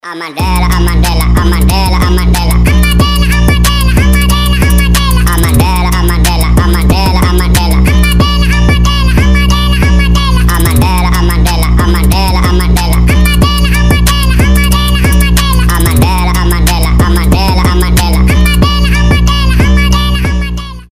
Цикличный гудок